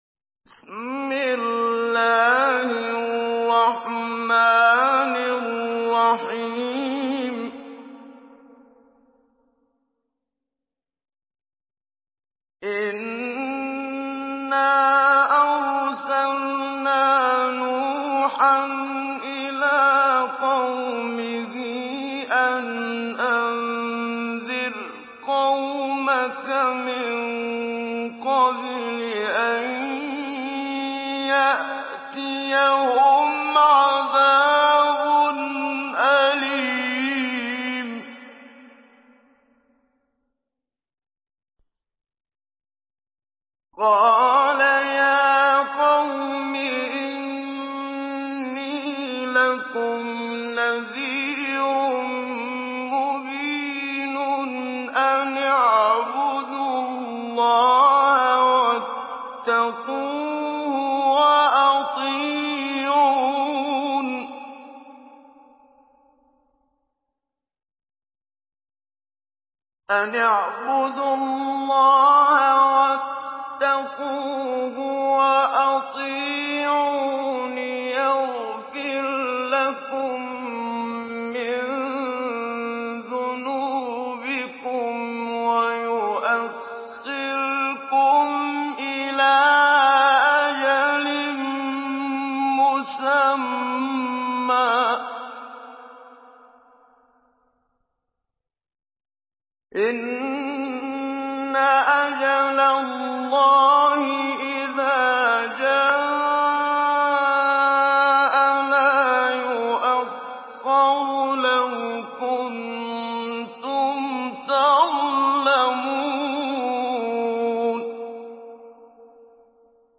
Surah Nuh MP3 in the Voice of Muhammad Siddiq Minshawi Mujawwad in Hafs Narration
Surah Nuh MP3 by Muhammad Siddiq Minshawi Mujawwad in Hafs An Asim narration.